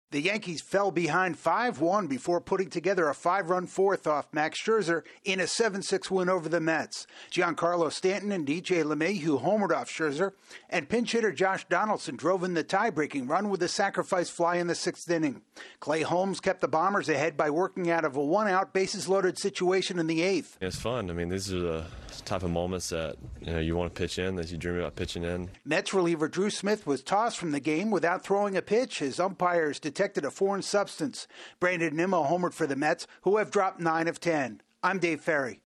The Yankees erase a four-run deficit before holding on to beat the Mets. AP correspondent